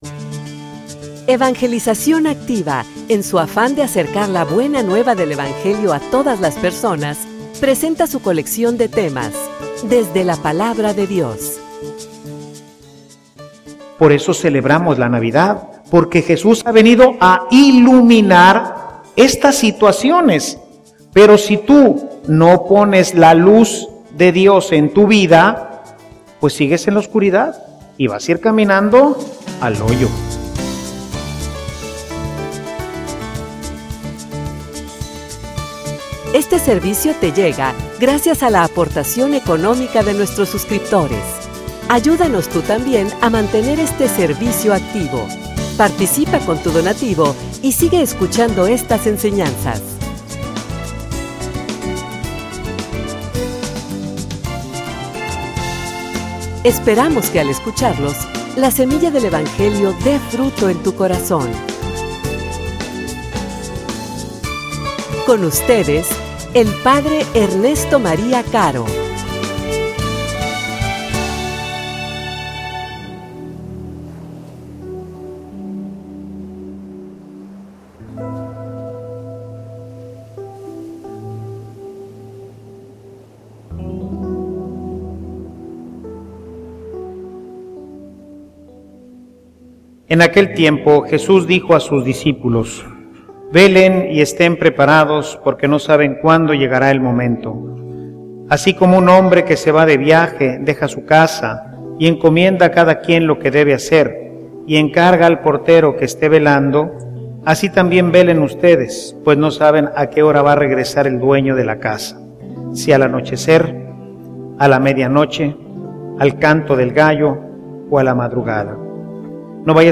homilia_Enfocate.mp3